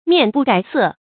面不改色 注音： ㄇㄧㄢˋ ㄅㄨˋ ㄍㄞˇ ㄙㄜˋ 讀音讀法： 意思解釋： 面：顏面；色：顏色。